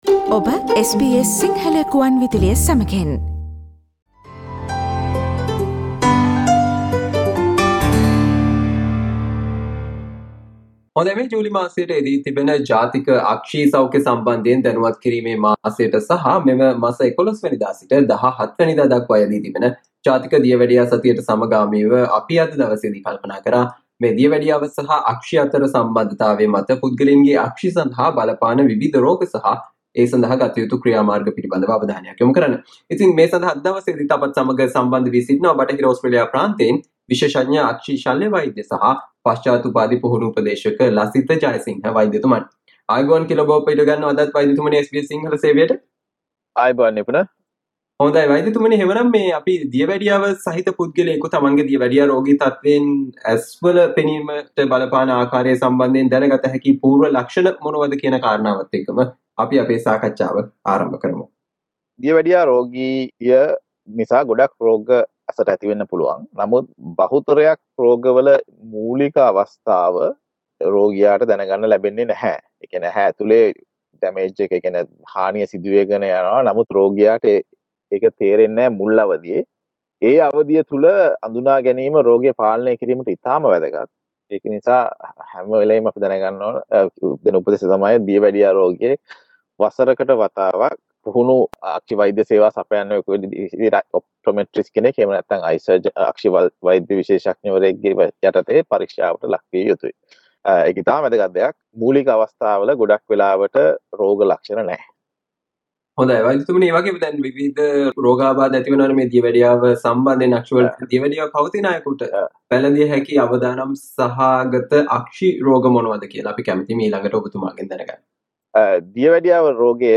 ජූලි මාසයට යෙදී තිබෙන ජාතික අක්‍ෂි සෞඛ්‍යය සම්බන්ධයෙන් දැනුවත් කිරීමේ මාසයට සහ 11 වැනිදා සිට 17 වැනිදා දක්වා යෙදෙන ජාතික දියවැඩියා සතියට සමගාමීව දියවැඩියාවෙන් අක්ෂි සඳහා බලපාන විවිධ රෝග සහ අසාදන සම්බන්ධයෙන් SBS සිංහල සේවය සිදු කල සාකච්චාවට ඔබට මේ ඔස්සේ සවන් දිය හැක.